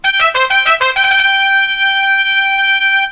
SFX冲锋号音效下载
SFX音效